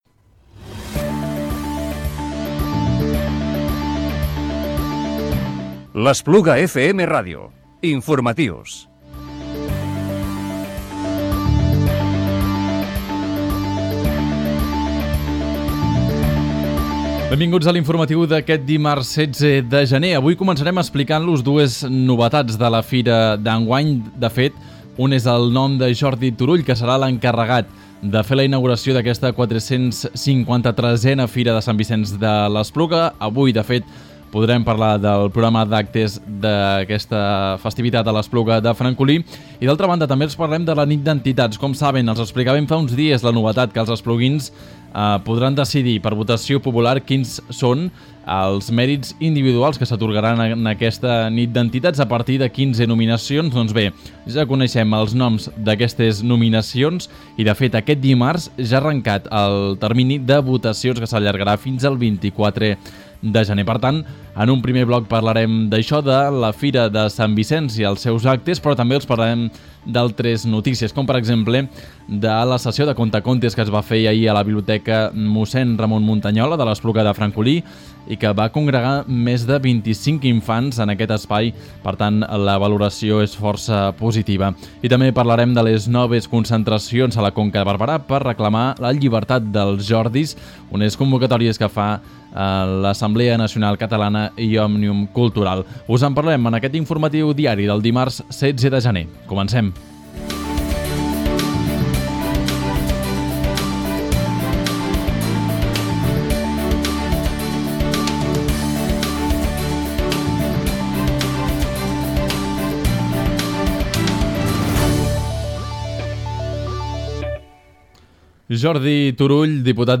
Informatiu Diari del dimarts 16 de gener del 2018